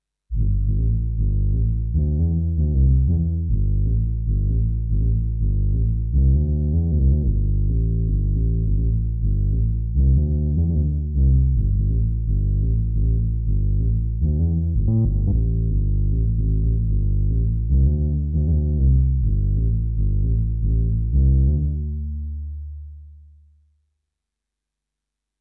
Бас муг плюс немного туалетного эфекта от лексикона рсм 70. Записано в рме фф 800. Потом легкий нормалайз в форже по пикам. После это операции шум 54 дб, а до нее в пределах 60. Тоесть шум плюс 6 дб.